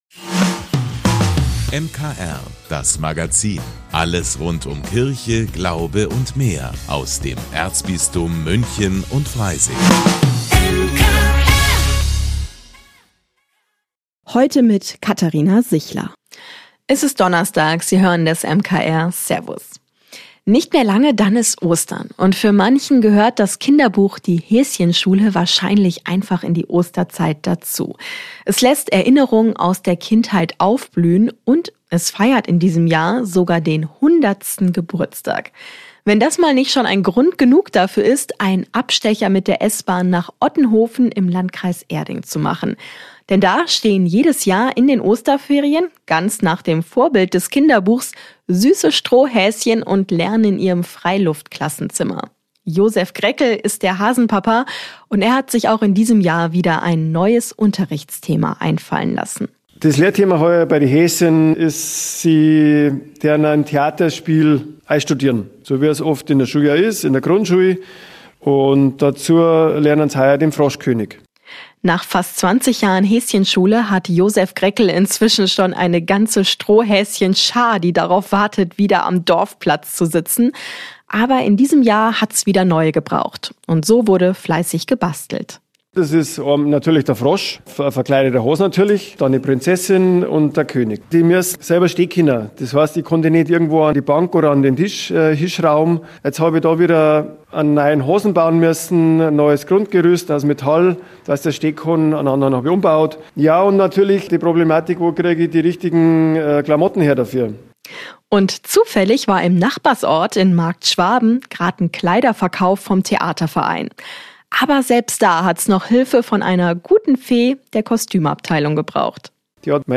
Wir haben Gymnasiasten aus Haar gefragt, wie sie die Osterzeit verbringen, auf was sie sich an Ostern besonders freuen.